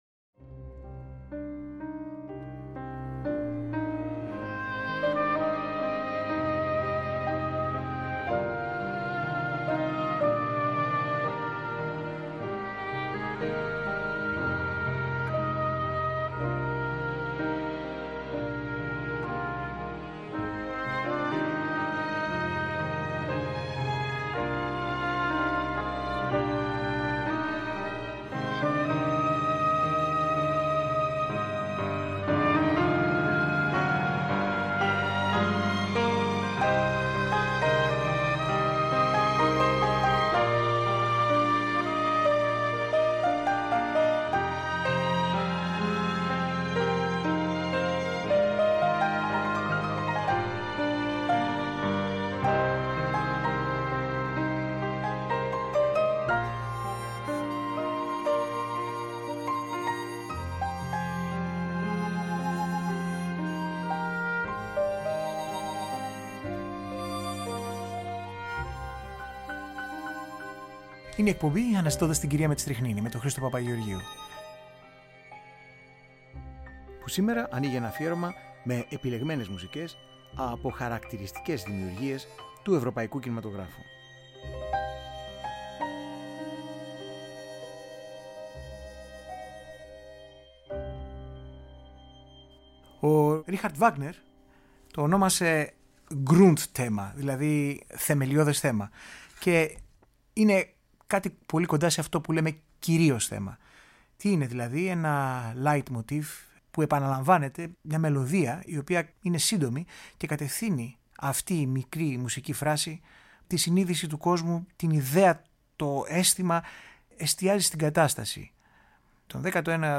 Original Soundtrack
κινηματογραφικη μουσικη